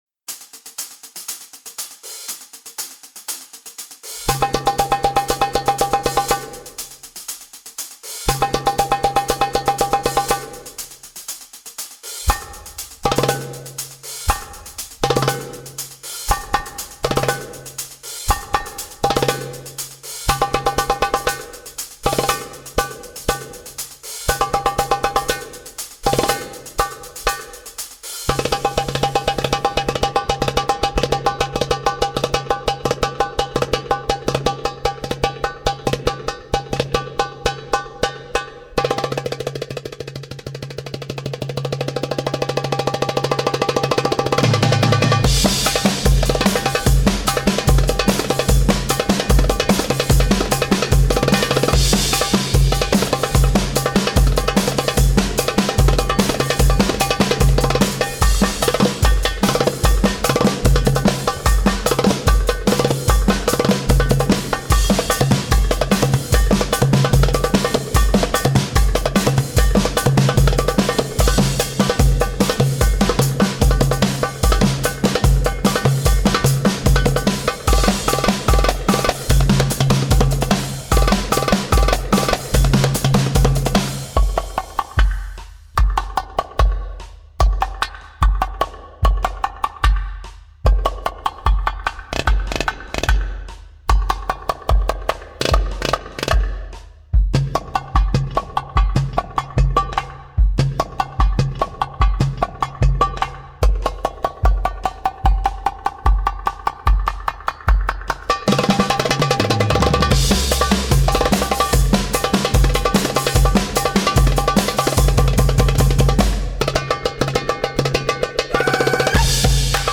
Carpeta: musica hindu mp3
Drum Solo